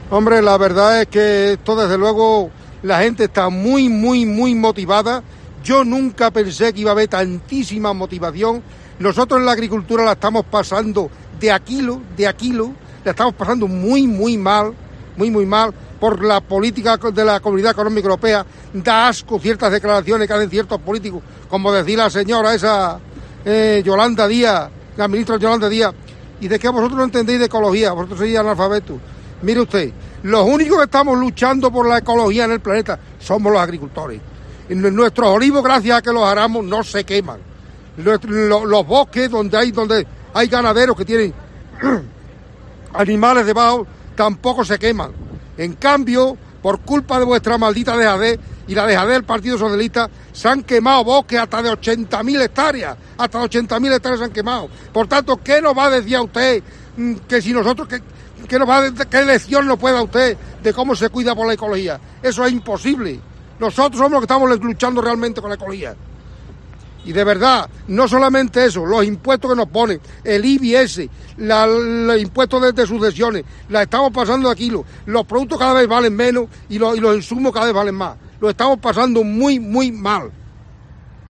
Nuestro protagonista era uno de los que cerraba la caravana de medio centenar de tractores que, pasadas las 13.15 horas, circulaban lentamente de regreso por la avenida de Europa (tramo de la travesía de Almendralejo).